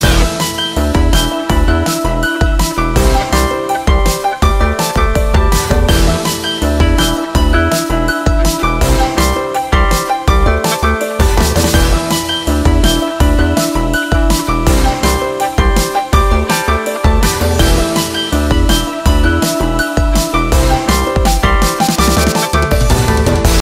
Drum and HARP & Guitar COVER